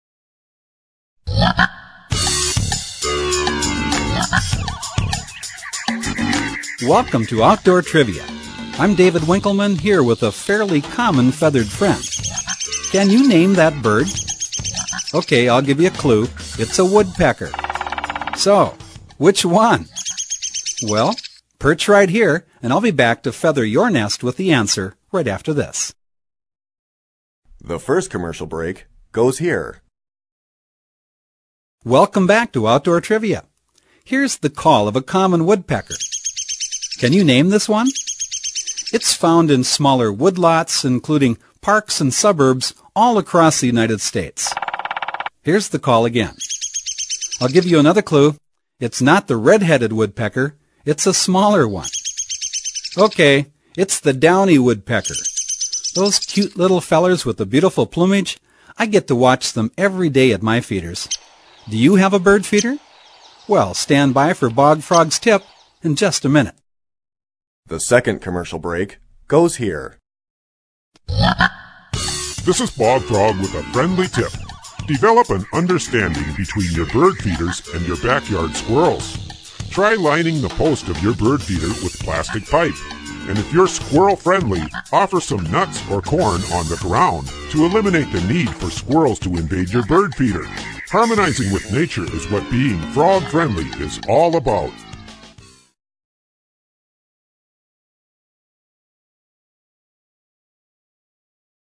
Tune in and play “Name that bird call!” Unlock the enigmas behind the sounds produced by gamebirds.
In fact, the question and answer trivia format of this program remains for
bird-calls-03.mp3